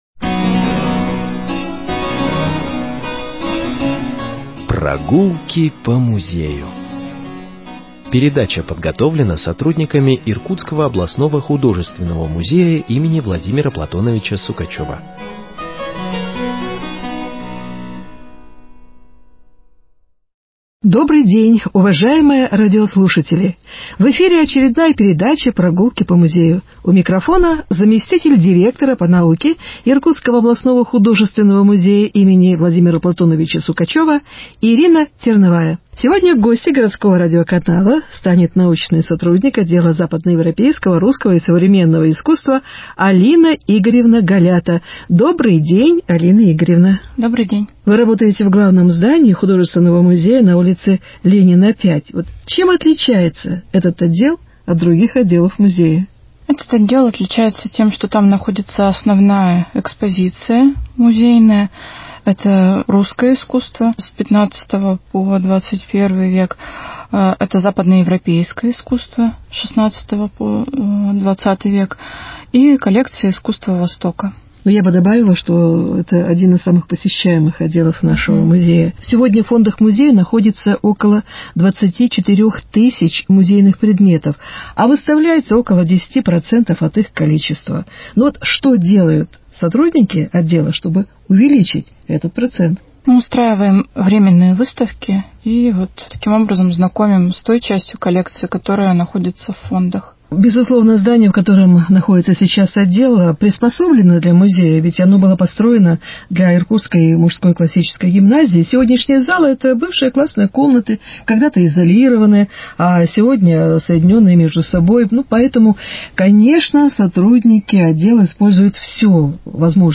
Беседа с научным сотрудником отдела западноевропейского